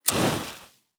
zippo_strike_success_01.wav